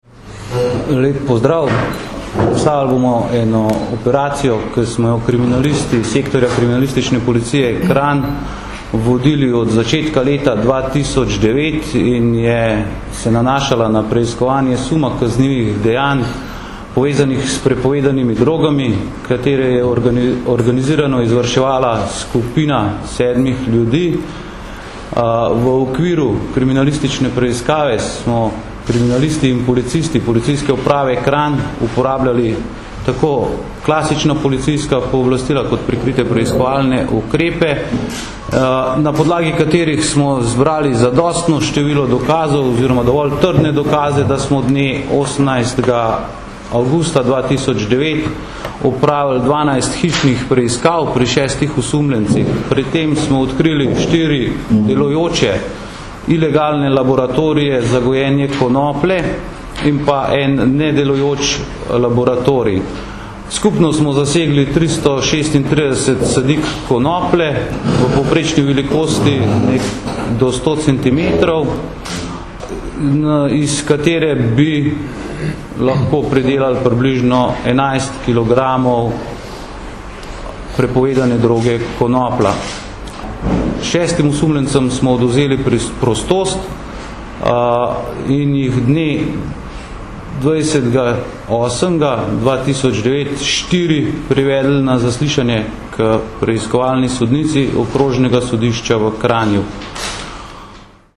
Policija - Razkrili kriminalno združbo preprodajalcev marihuane - informacija z novinarske konference